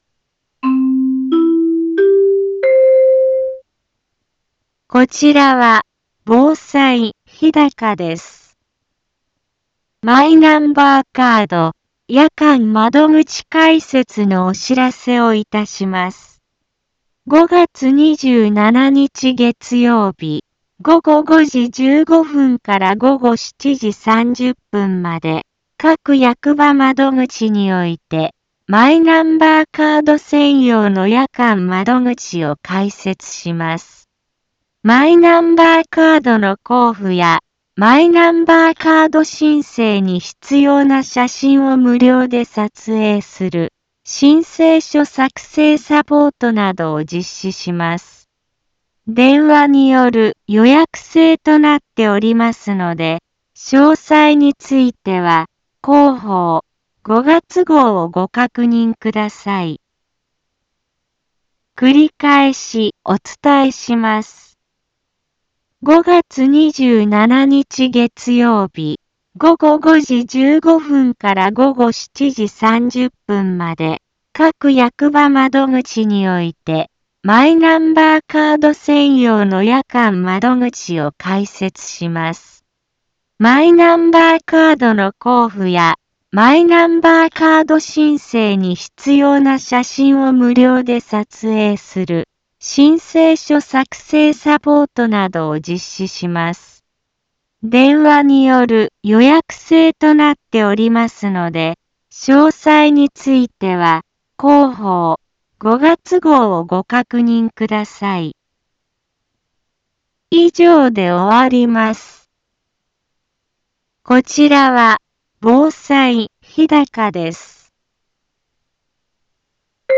一般放送情報
Back Home 一般放送情報 音声放送 再生 一般放送情報 登録日時：2024-05-17 10:04:18 タイトル：マイナンバーカード夜間窓口開設のお知らせ インフォメーション： マイナンバーカード夜間窓口開設のお知らせをいたします。 5月27日月曜日、午後5時15分から午後7時30分まで、各役場窓口において、マイナンバーカード専用の夜間窓口を開設します。